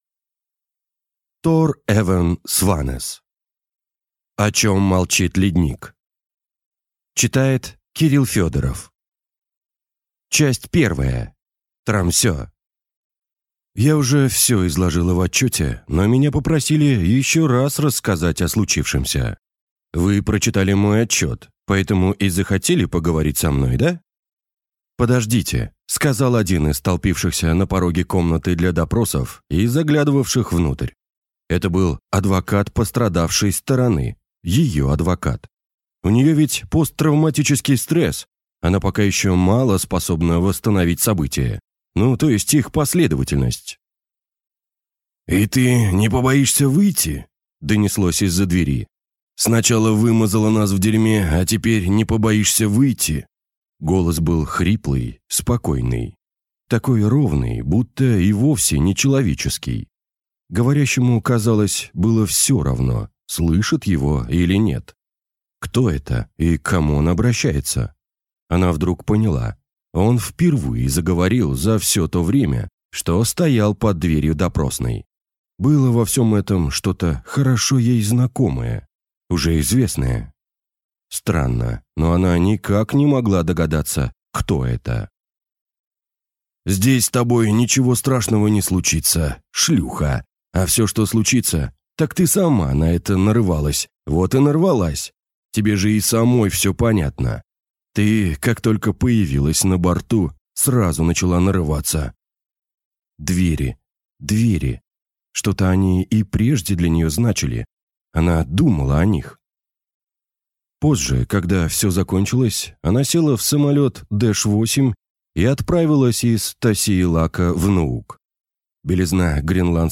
Аудиокнига О чём молчит ледник | Библиотека аудиокниг